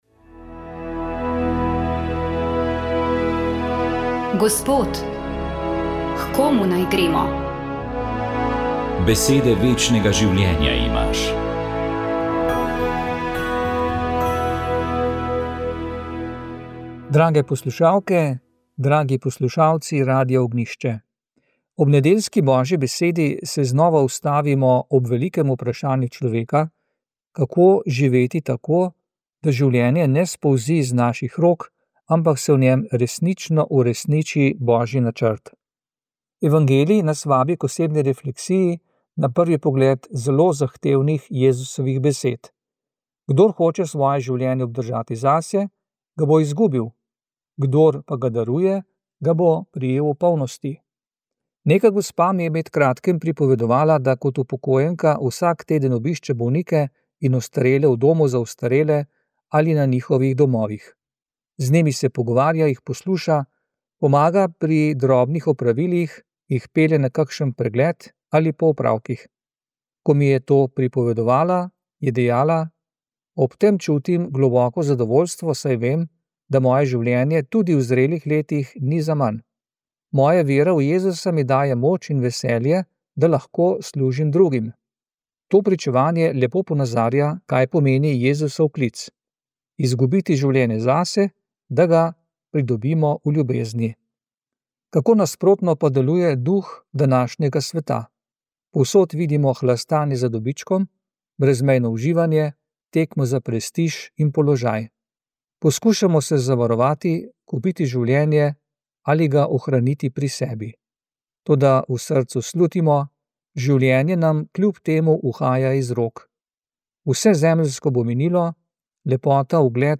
Duhovni nagovor